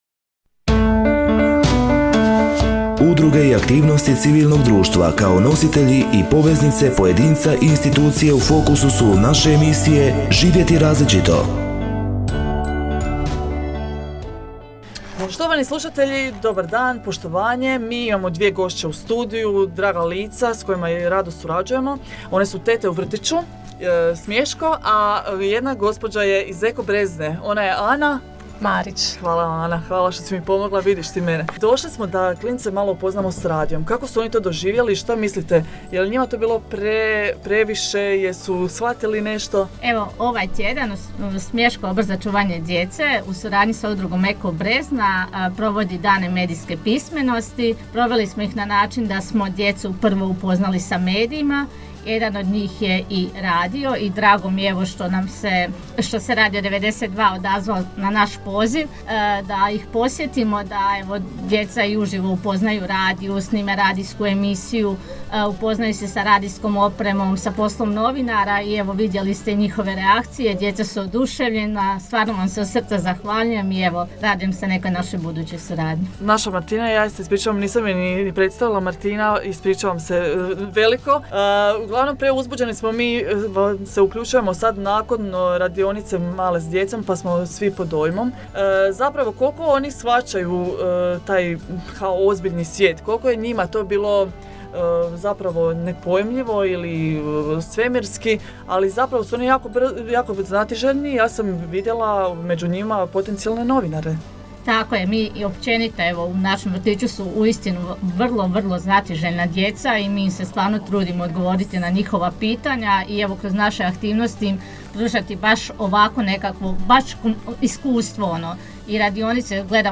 Gostovanje u radijskoj emisiji povodom obilježavanja Dana medijske pismenosti 2023. – Eko Brezna